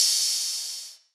DDWV OPEN HAT 1.wav